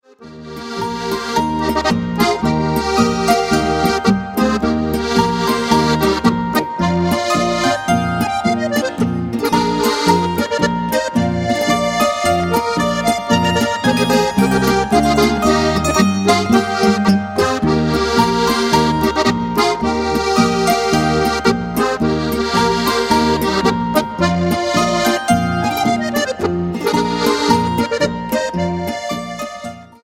Deutschland Hausmusik